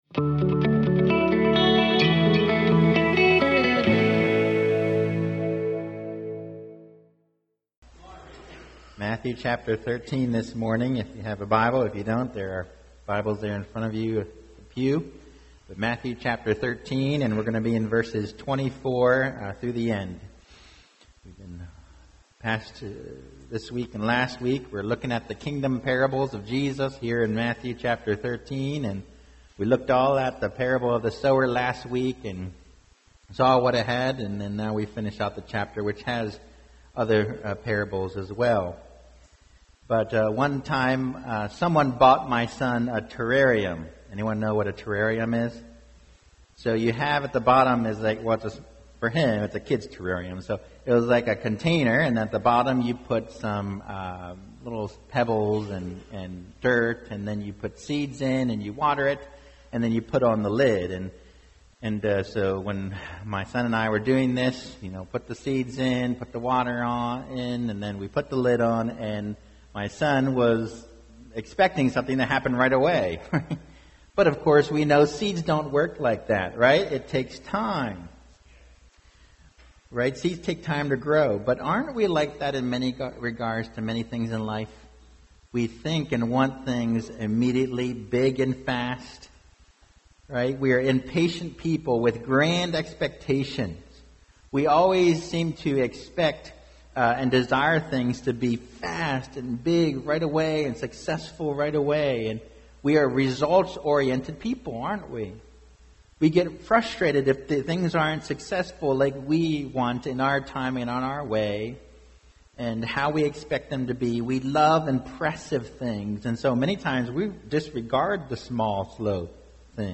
2023 The Surprising Nature of God’s Kingdom Preacher